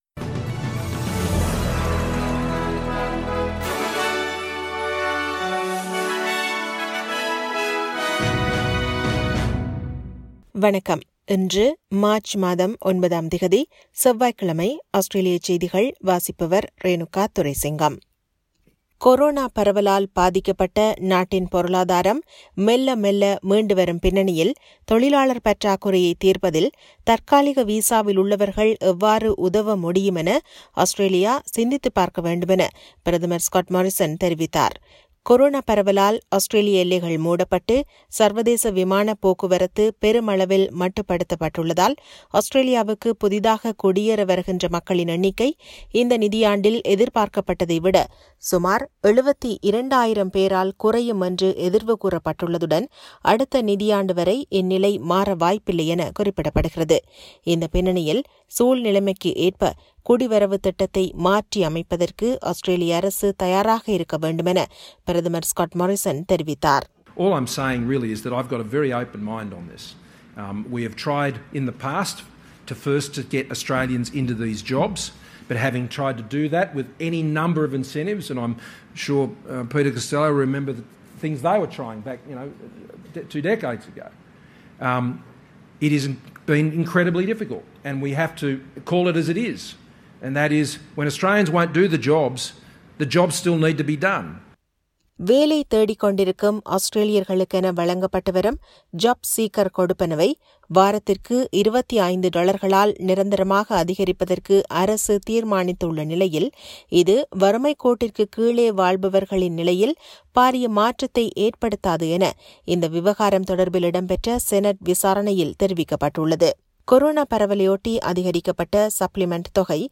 Australian news bulletin for Tuesday 09 March 2021.